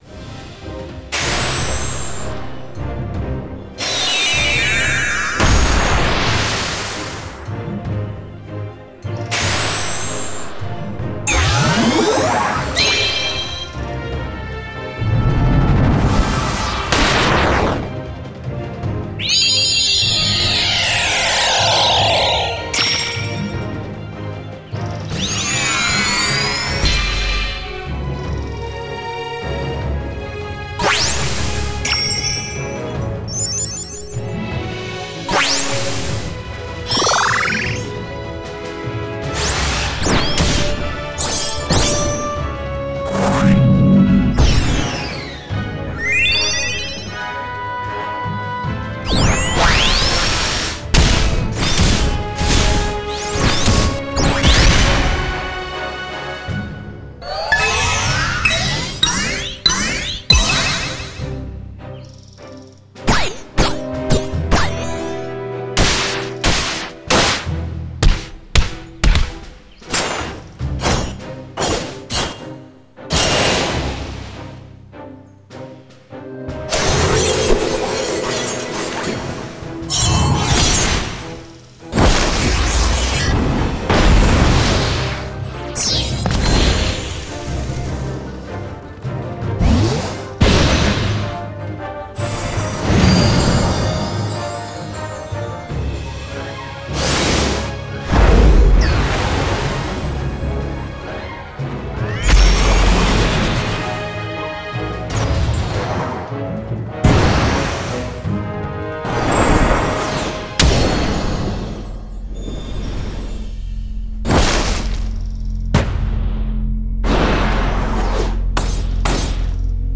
446魔法音效
与我们的RPG Magic音效包类似，我们的音效来自不同的层次，例如“投射”，“冲击”，“射击”，甚至还有更多JRPG设计的咒语层，可为您的游戏创建最合适的魔法声音。
• 魔法攻击与冲击（包括基本的飞快移动，复古的JRPG风格和现代的RPG风格法术）
• 元素法术（火，风，水，植物，电和冰）
• 武器和设计的法术（魔术拳，斜杠，组合法术，VFX启发法术）
• 拳，剑斜线等等！